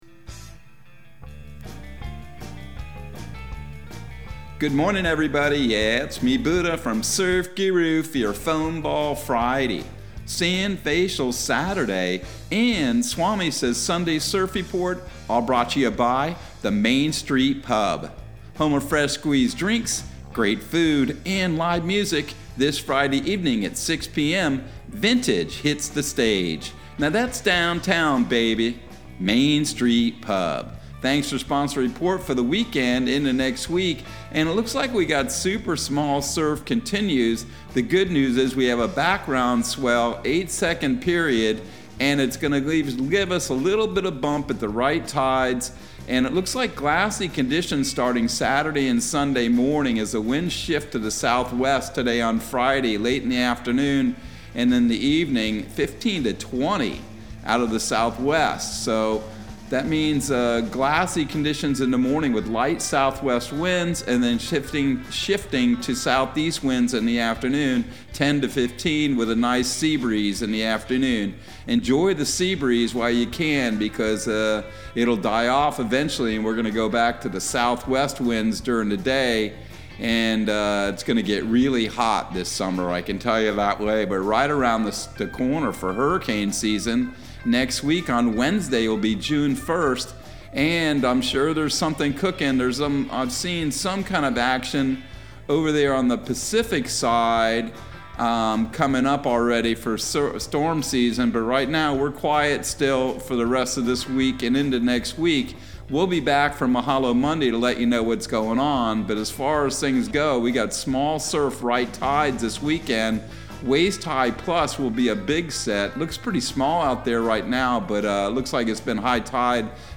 Surf Guru Surf Report and Forecast 05/27/2022 Audio surf report and surf forecast on May 27 for Central Florida and the Southeast.